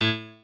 piano7_27.ogg